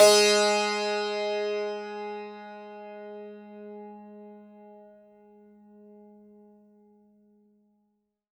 52-str03-sant-g#2.wav